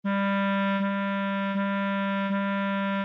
Piano (Corda percutida) | Orquestra de cARTón (ODE5)